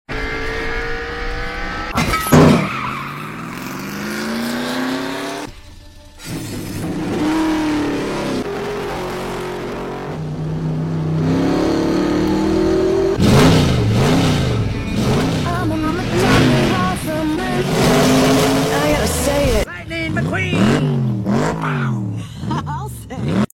McQueen engine sound ⚡⚡ sound effects free download